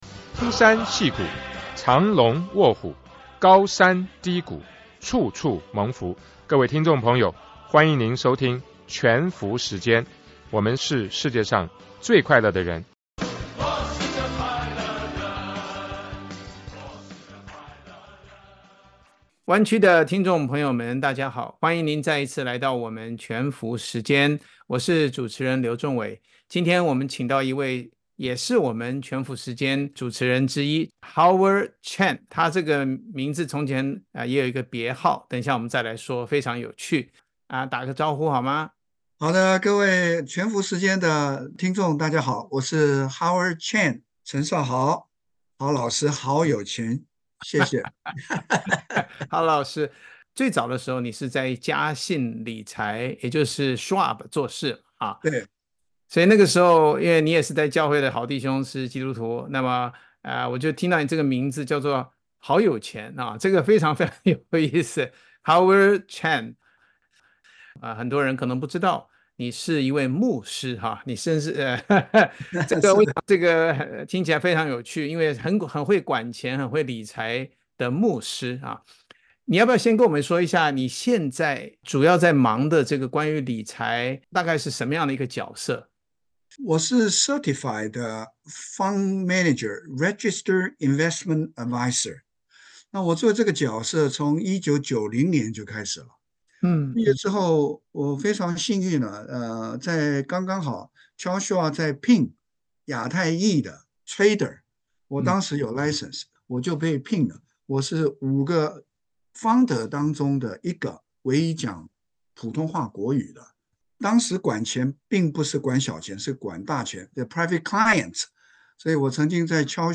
全福時間FM廣播節目剪輯